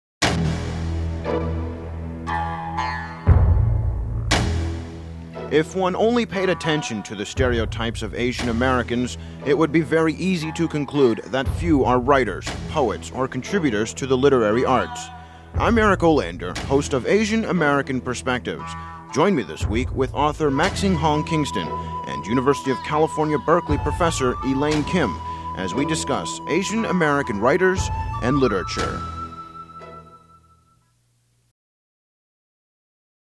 Each show was accompanied by a 30 second promotional spot that NPR affiliates could run during the week to invite listeners to tune in to Asian American Perspectives.
AA writers promo